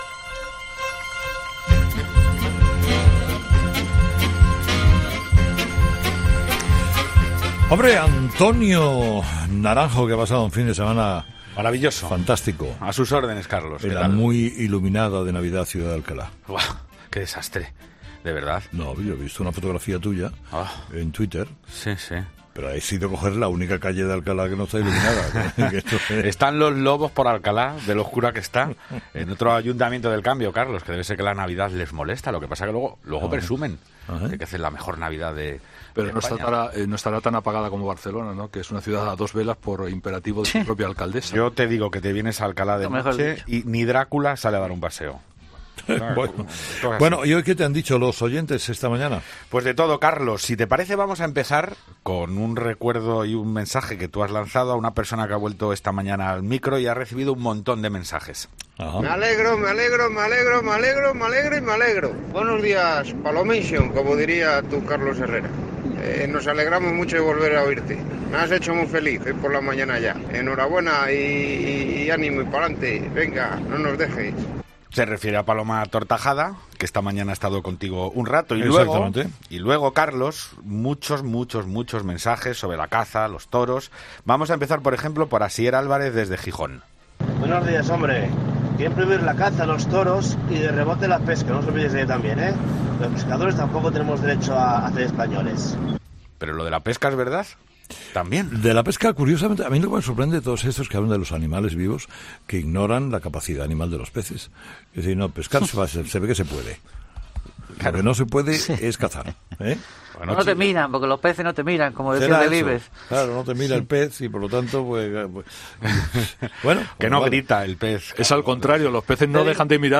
La tertulia de los oyentes es el espacio que Carlos Herrera ofrece a sus seguidores para que pongan sobre la mesa las opiniones sobre los temas de actualidad que les preocupan.